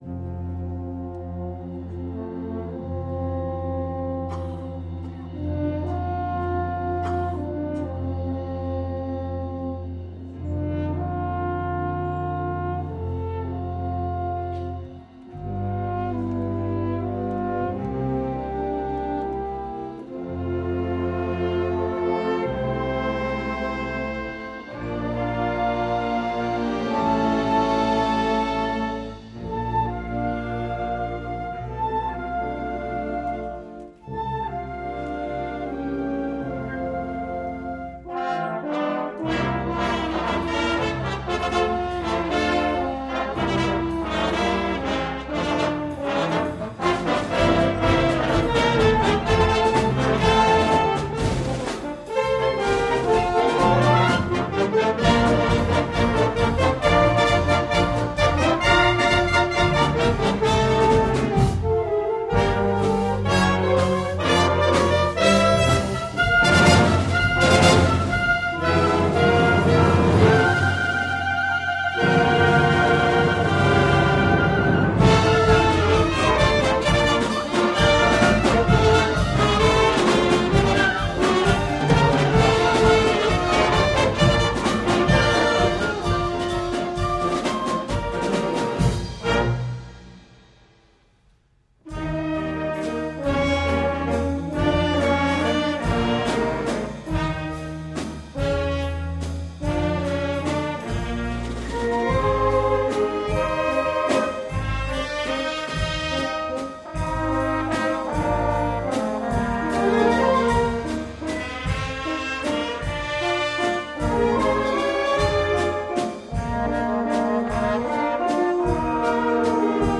Concert de Noël 2010